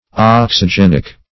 Search Result for " oxygenic" : The Collaborative International Dictionary of English v.0.48: Oxygenic \Ox`y*gen"ic\, a. (Chem.) Pertaining to, containing, or resembling, oxygen; producing oxygen.